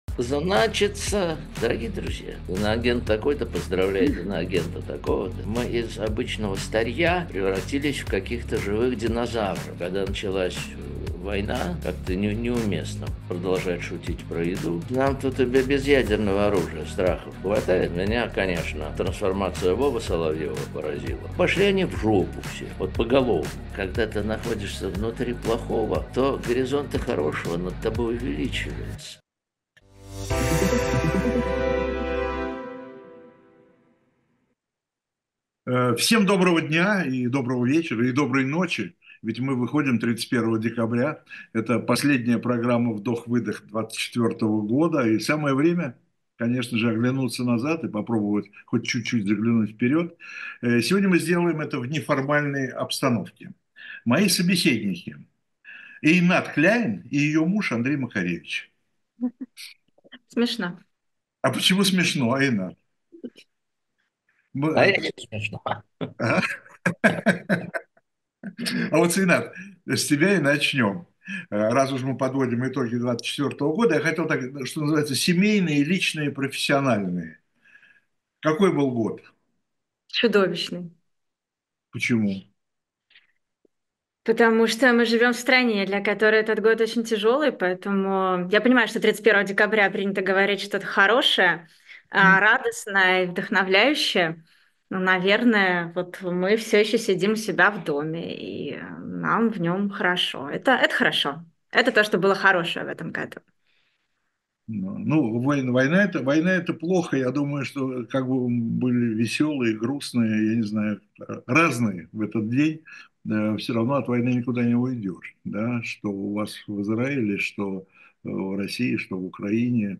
Эфир ведёт Виталий Дымарский